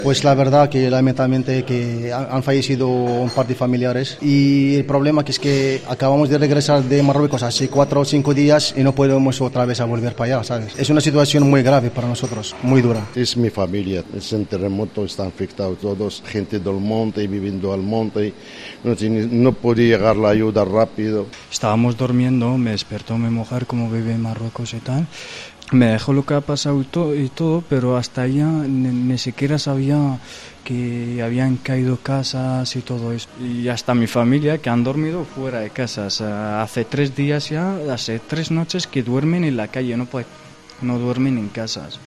Escucha aquí los testimonios de marroquíes en Aragón tras el terremoto